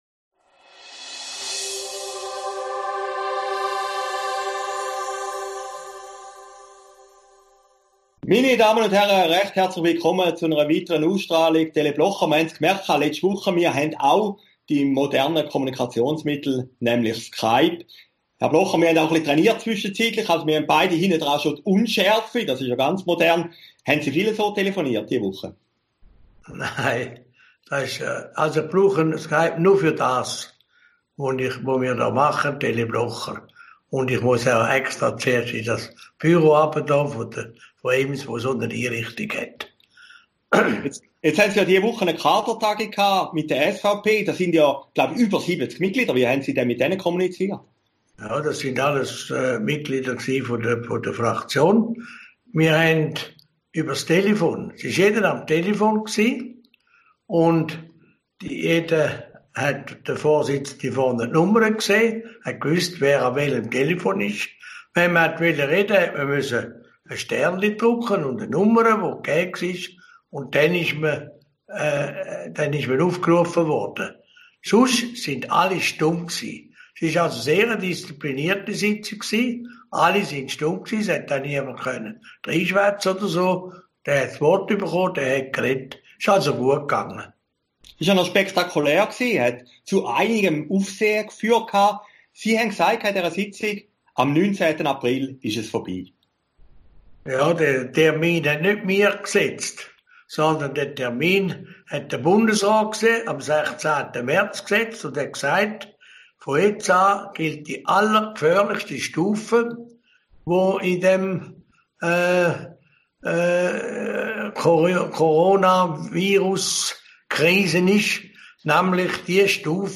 Aufgezeichnet in Herrliberg und Zürich, 3. April 2020